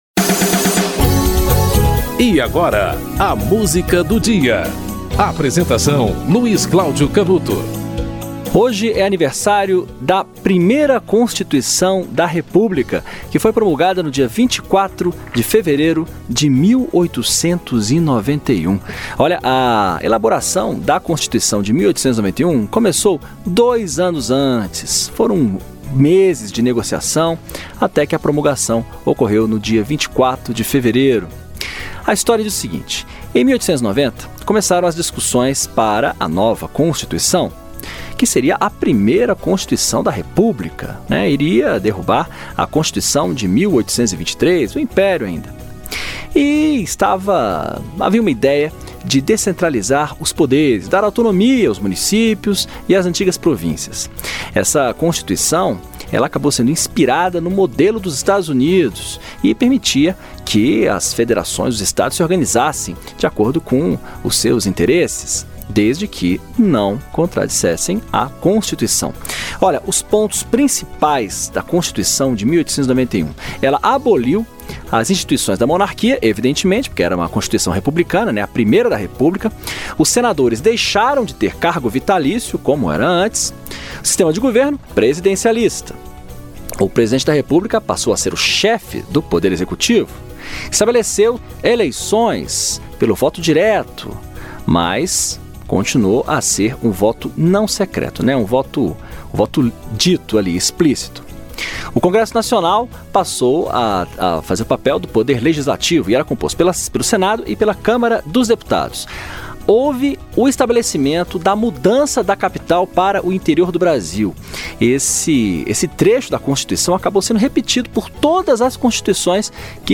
Philarmonia Orchestra - Tormenta de Neve (Sergei Prokofiev)